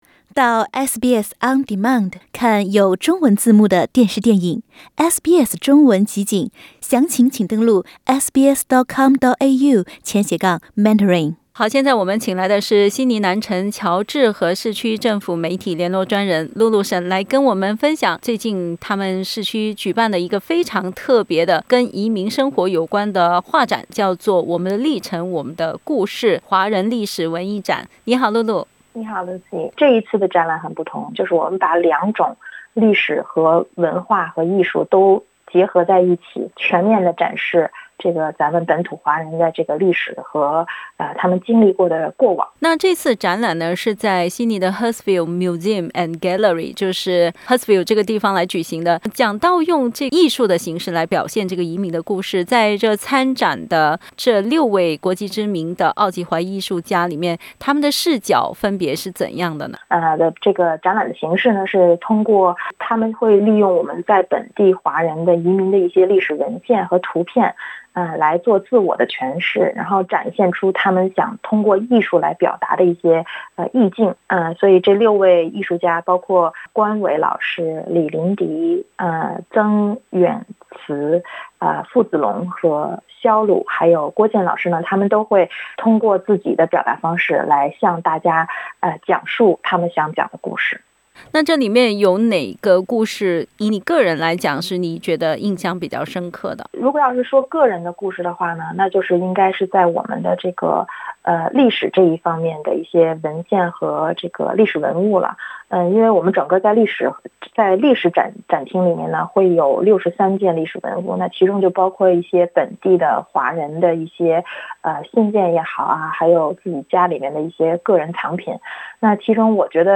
（請聽寀訪） 澳大利亞人必鬚與他人保持至少1.5米的社交距離，請查看您所在州或領地的最新社交限制措施。